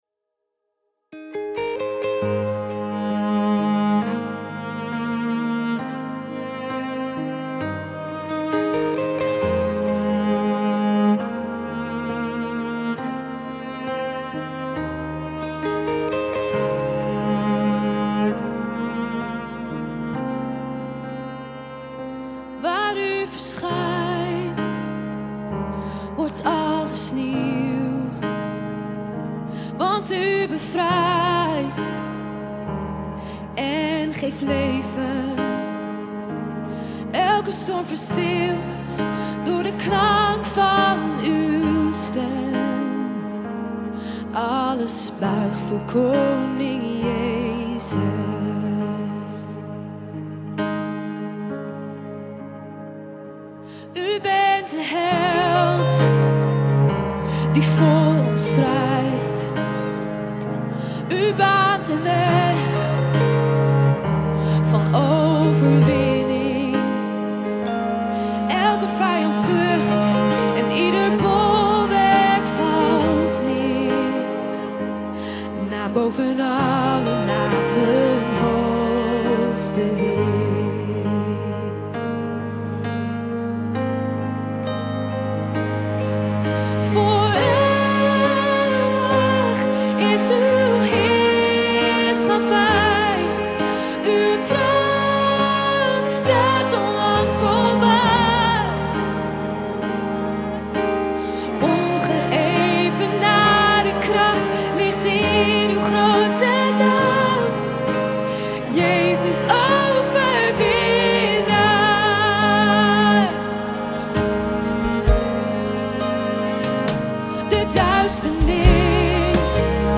Новые христианские песни с аккордами